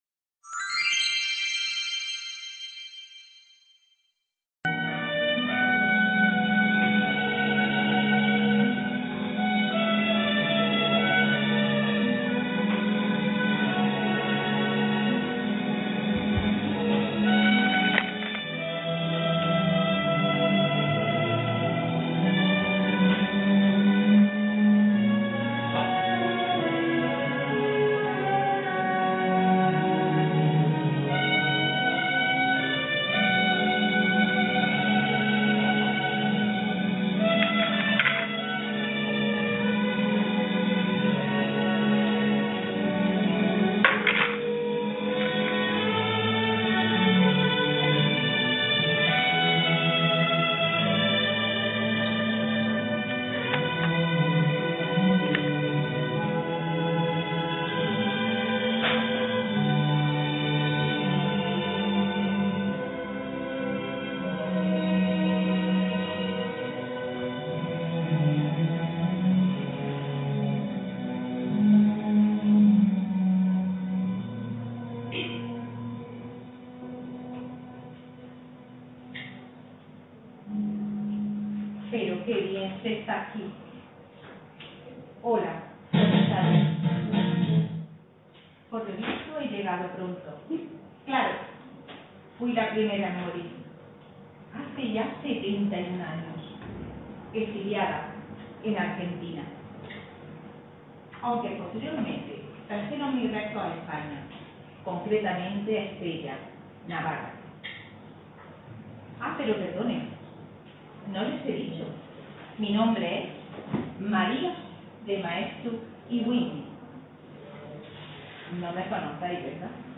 El Club de las Maridas: El Lyceum Club 1926. Representacion de la obra. Description Se recoge la trayectoria de grandes mujeres anteriores en el tiempo y centrando la atención en el encuentro de su "espacio" propio: El Lyceum Club.